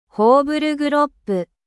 translate_tts.mp3